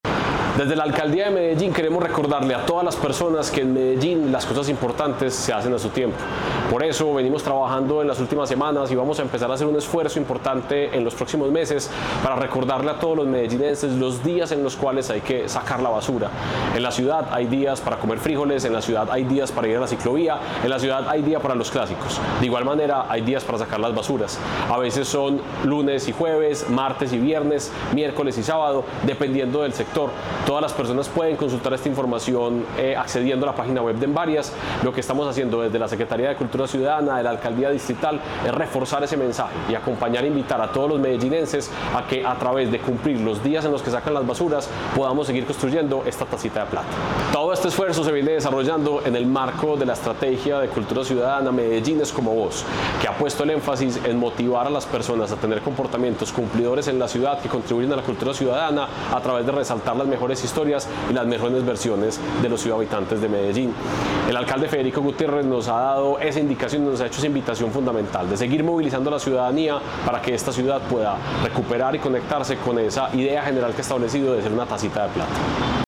Palabras de Santiago Silva, secretario de Cultura Ciudadana